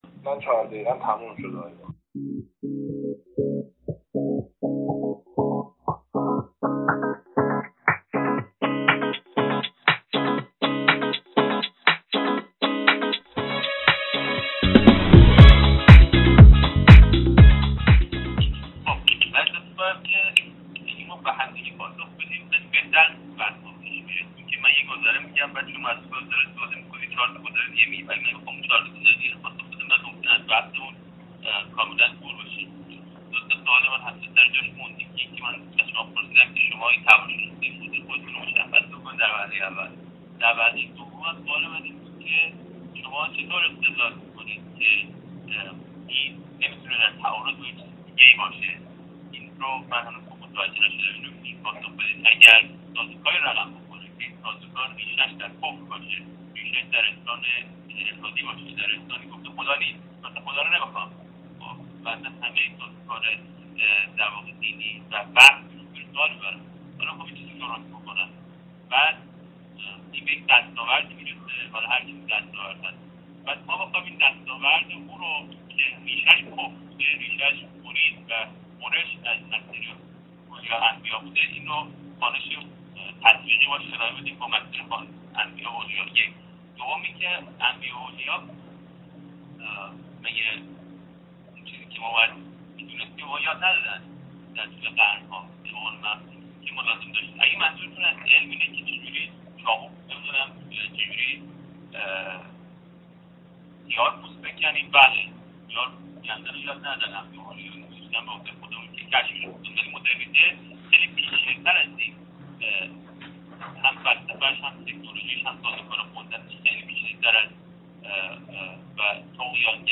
Debate2.mp3